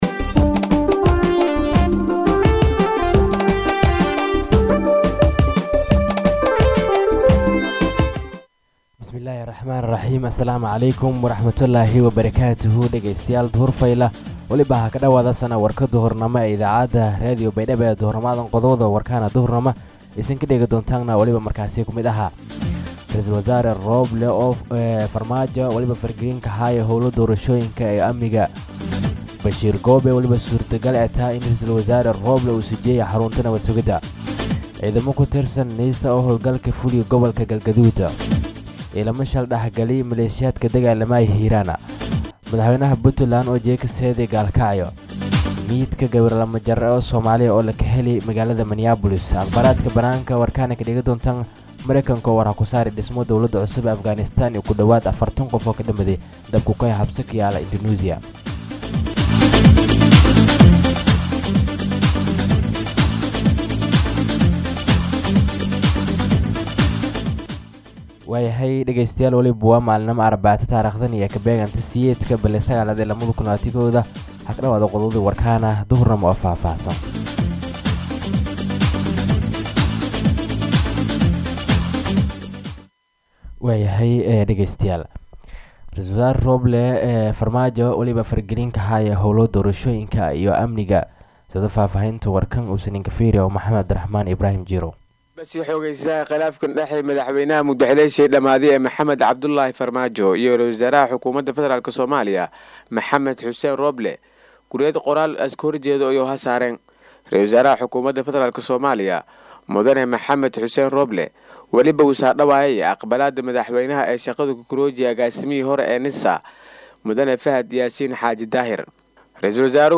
DHAGEYSO:-Warka Duhurnimo Radio Baidoa 8-9-2021